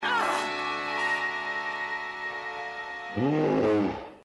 grunts in pain and falls over dead, and the movie's over.
slashdance-roar.mp3